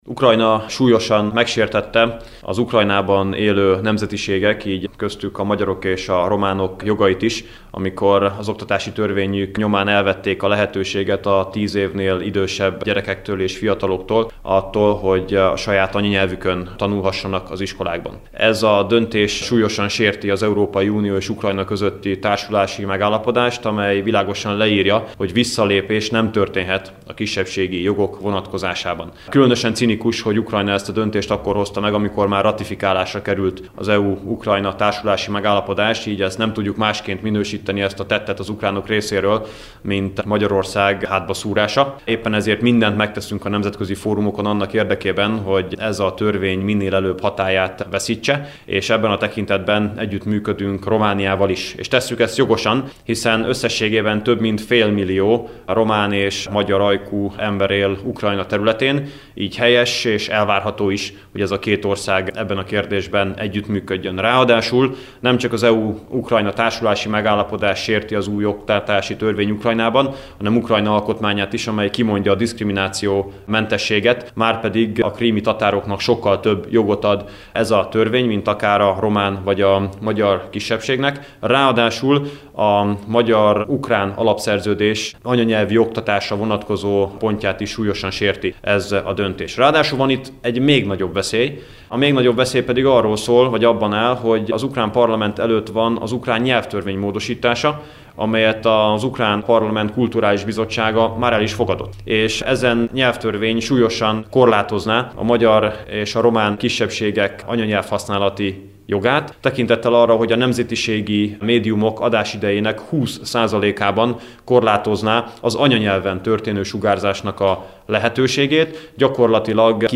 Szijjártó Pétert hallják.